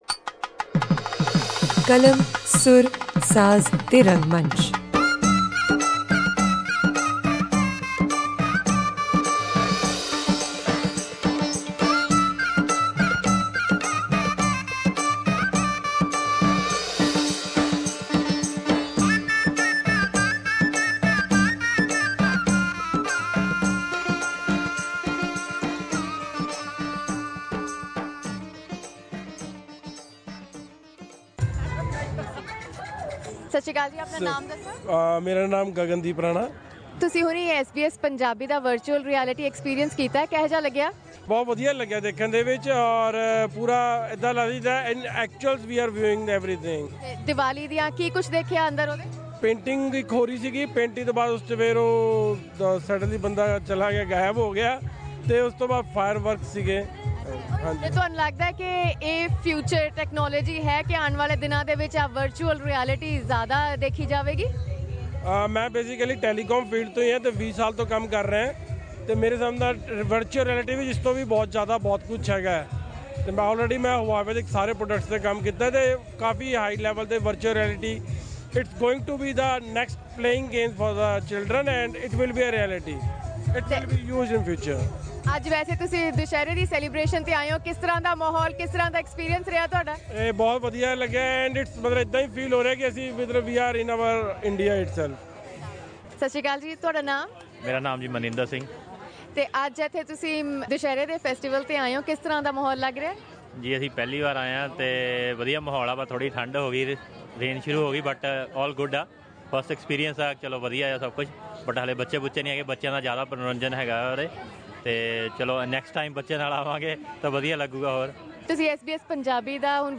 SBS Punjabi was present on the day to capture the action and here's a glimpse of the day for our listeners to enjoy.